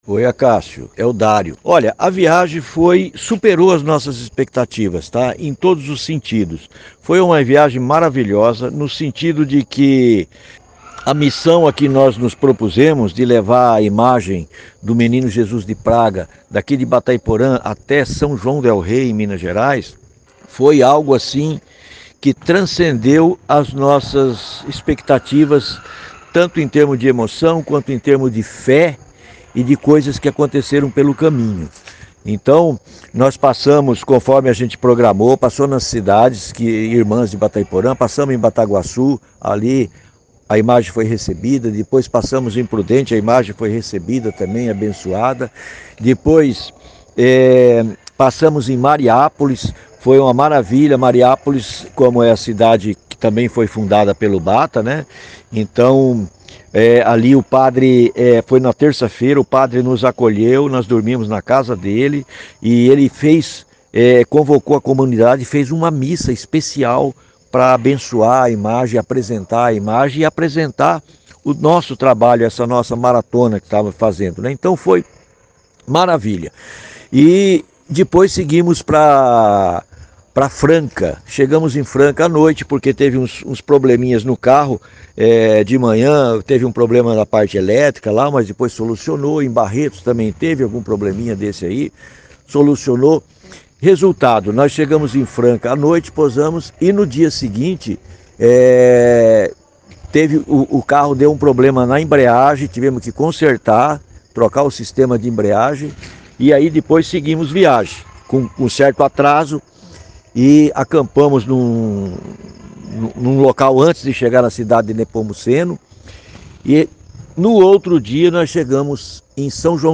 depoimento em áudio